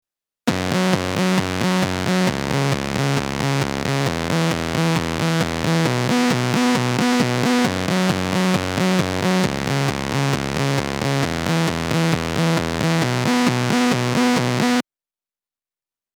JH_Vsynth_Chorus.mp3